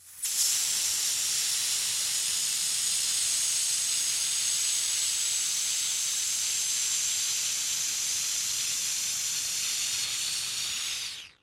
Звуки шиномонтажа: сдувание колес, замена на летние и балансировка